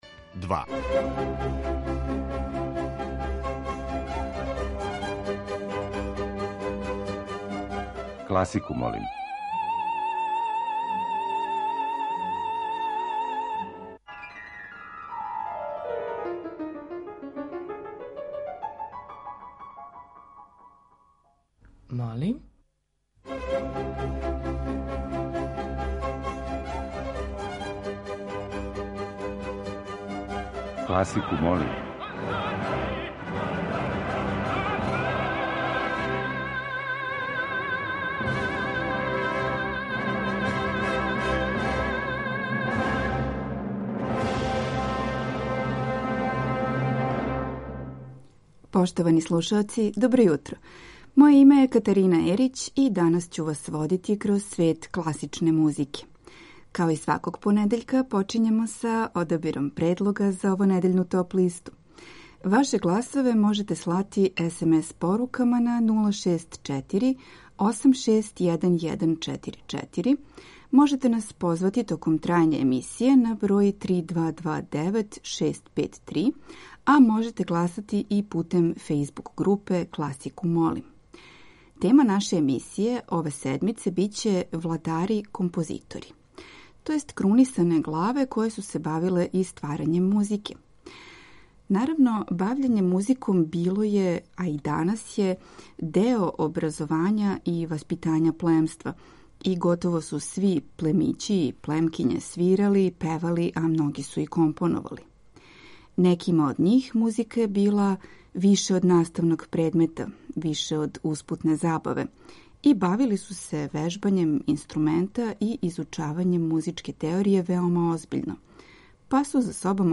Избор за топ-листу класичне музике